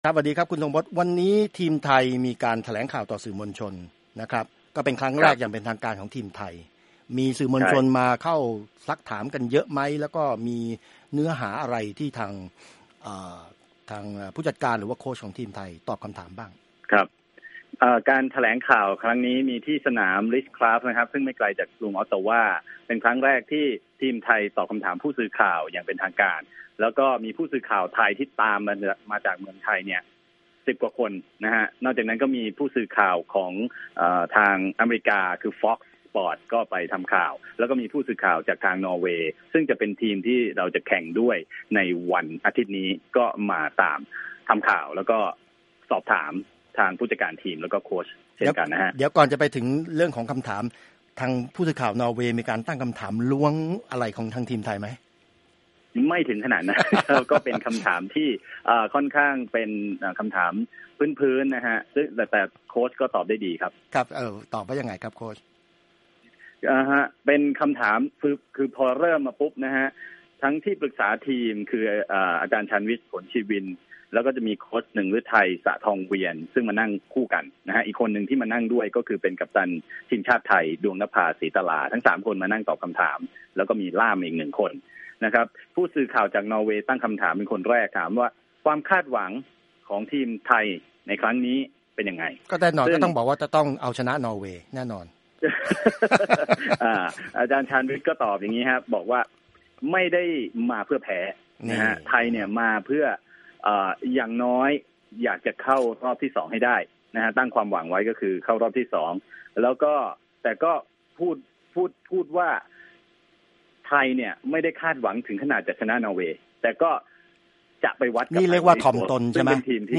Thai Women World Cup Press Conference
Thai Women Soccer First Press Conference